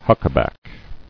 [huck·a·back]